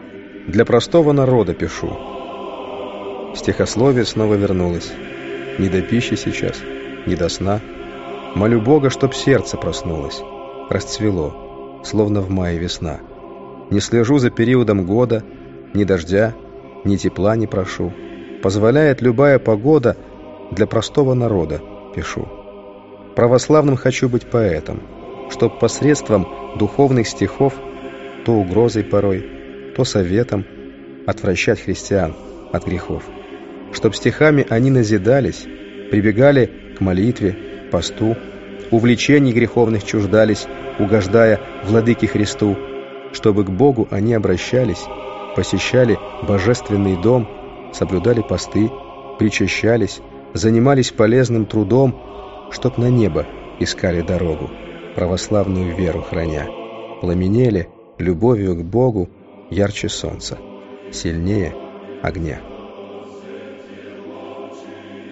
Аудиокнига Призыв к покаянию. Стихи | Библиотека аудиокниг